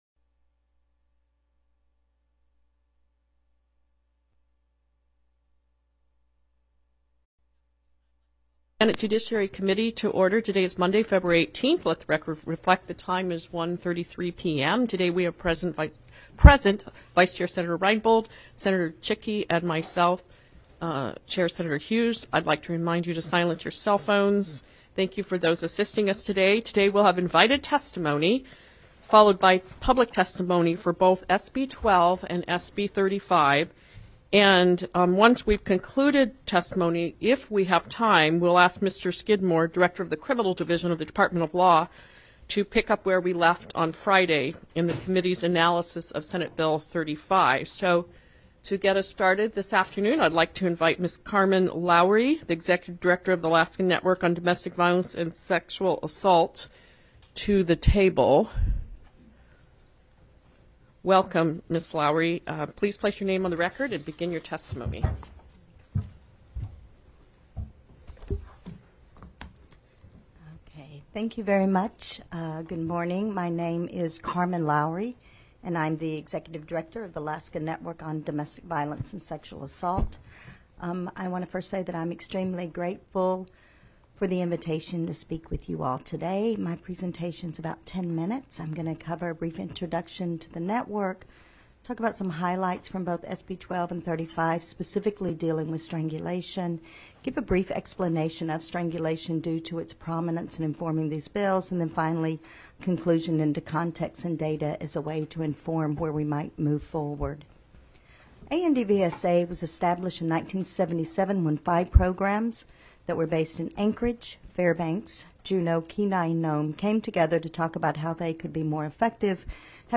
The audio recordings are captured by our records offices as the official record of the meeting and will have more accurate timestamps.
-Invited Testimony Followed by Public Testimony-